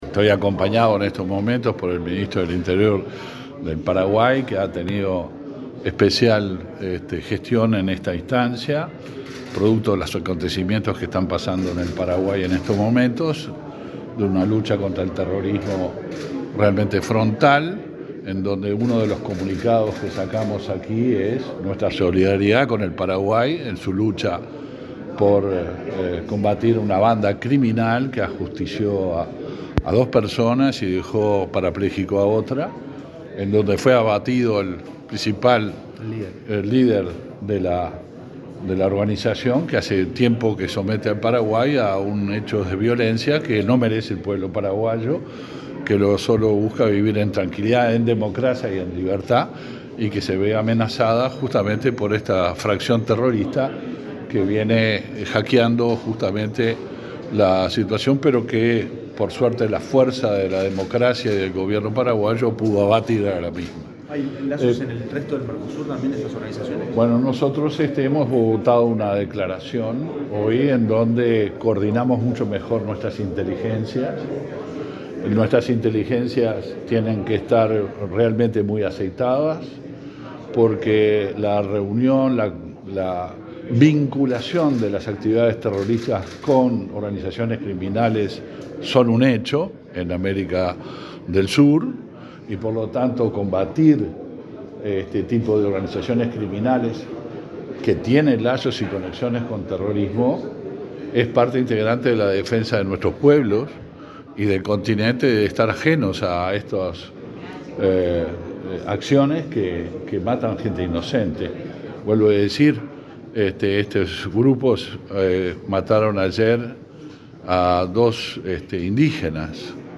Declaraciones de los ministros del Interior de Uruguay y Paraguay
El ministro del Interior, Luis Alberto Heber, y su par paraguayo, Federico González, dialogaron con la prensa luego de participar en la XLVIII Reunión